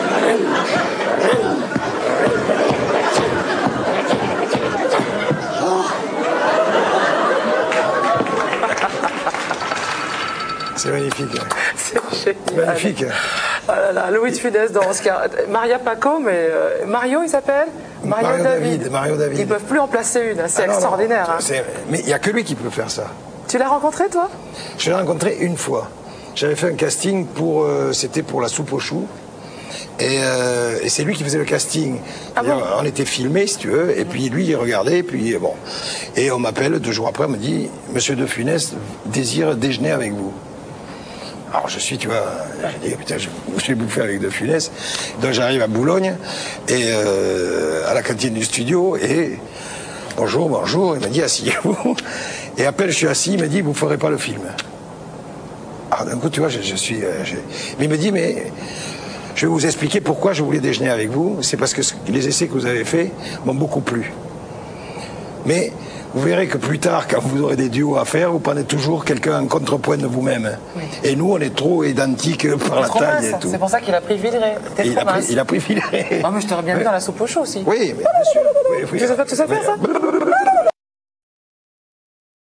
Cet extrait provient de l'émission "??? " diffusée en 2002 ...